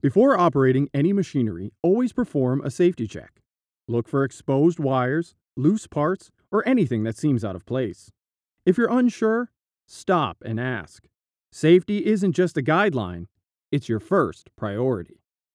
Male
Yng Adult (18-29), Adult (30-50)
E-Learning
Safety Video Voiceover